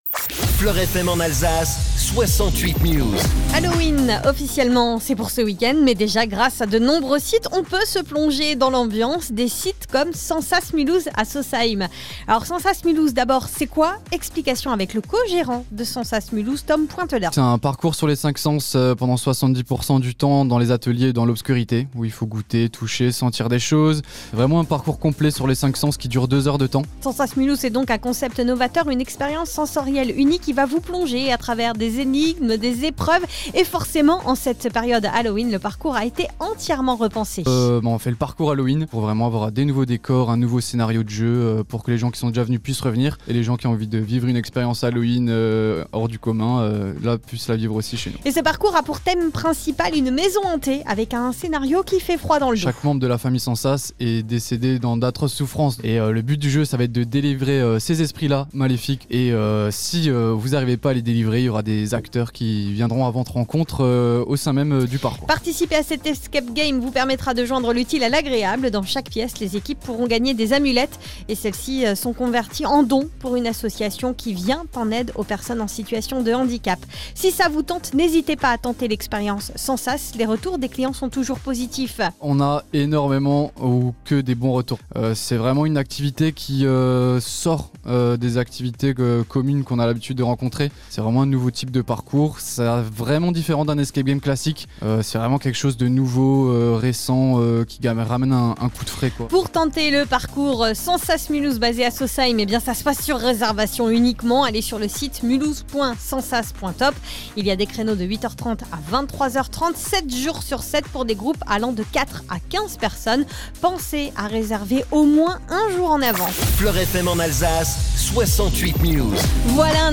FLOR FM : Réécoutez les flash infos et les différentes chroniques de votre radio⬦